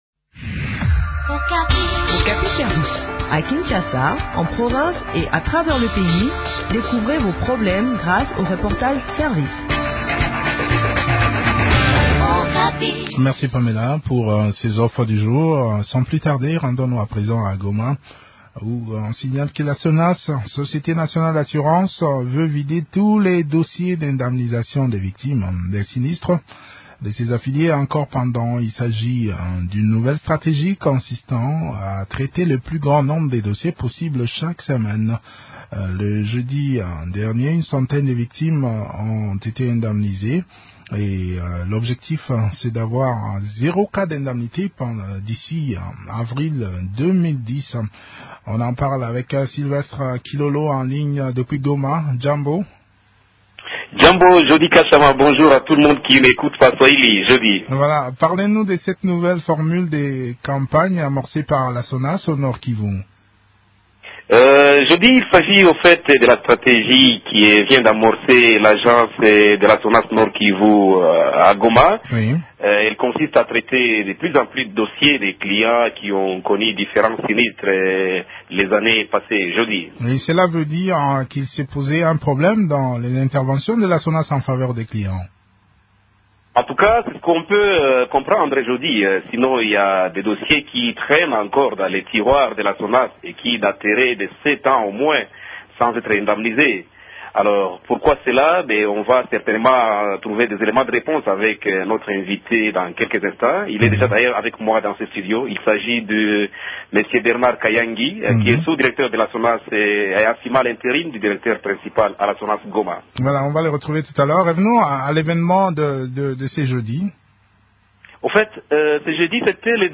Tous les dossiers litigieux doivent être traités d’ici avril 2010, affirme-t-on à la Sonas. Des précisions dans cet entretien